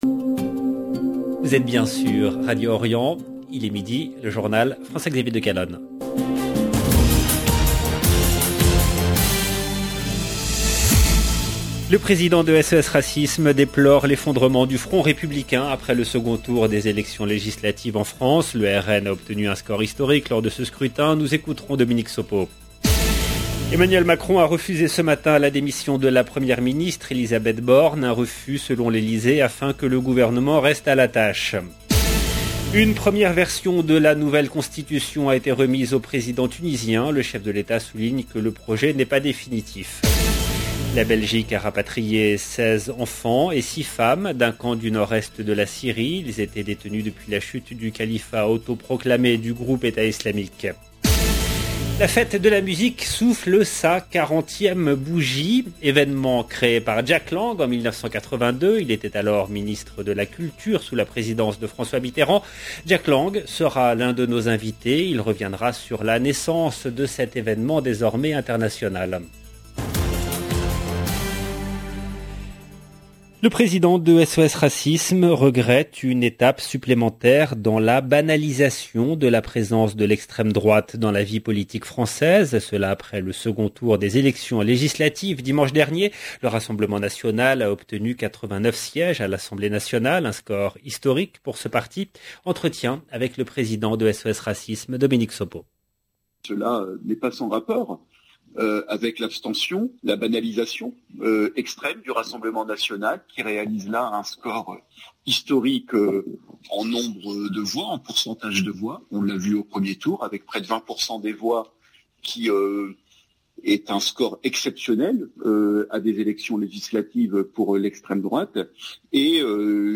Jack Lang sera l’un de nos invités. Il reviendra sur la naissance de cet événement désormais international. 0:00 17 min 16 sec